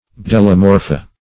Search Result for " bdellomorpha" : The Collaborative International Dictionary of English v.0.48: Bdellomorpha \Bdel`lo*mor"pha\,n. [NL., fr. Gr. bde`lla leech + morfh` form.]